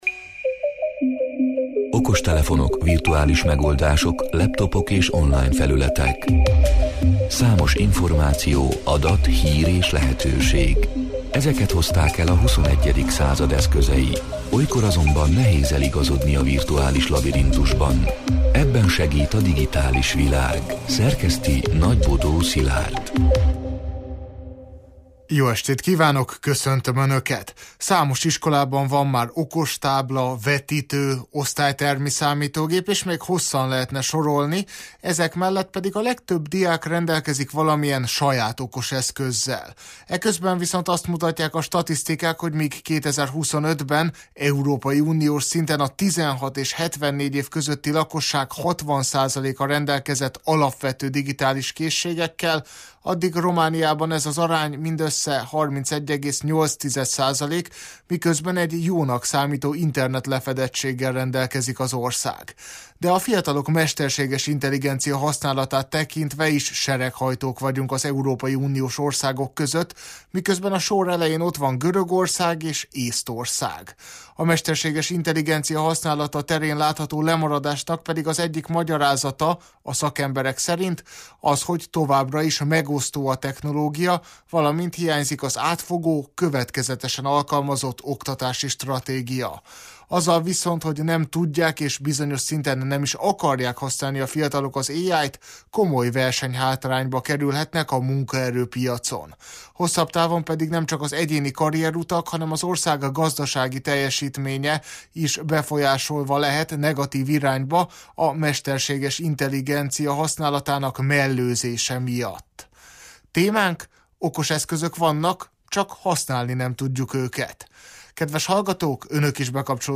A Marosvásárhelyi Rádió Digitális Világ (elhangzott: 2026. március 10-én, kedden este órától élőben) c. műsorának hanganyaga: